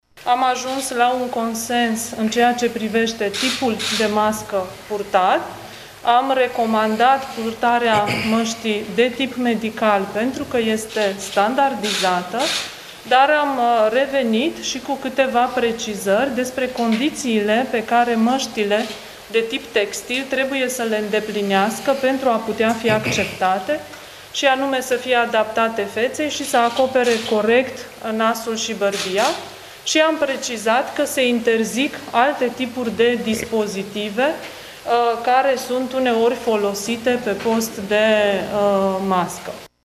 La rândul său, ministrul Sănătății a precizat că accesul în școli se va face cu măști și cu respectarea măsurilor de prevenție cum ar fi distanțarea și aerisirea cvasipermanentă.